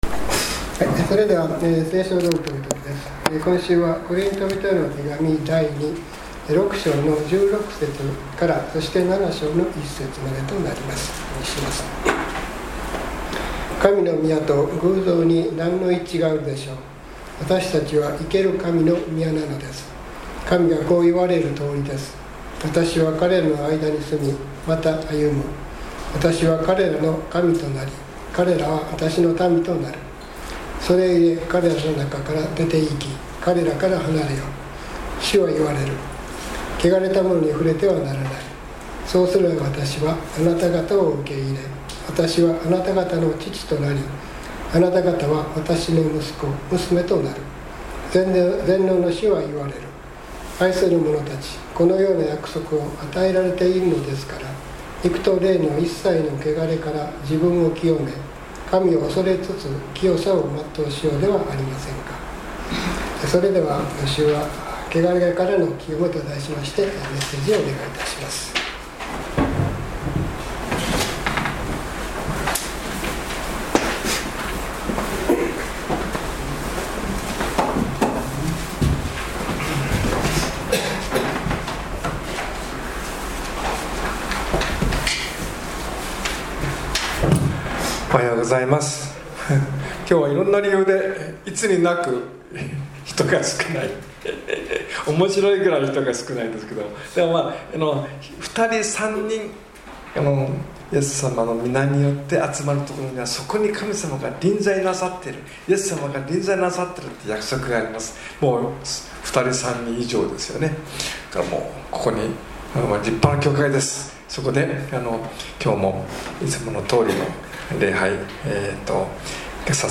３月１５日の礼拝メッセージ「汚れからの清め」Ⅱコリント６：１６－７：１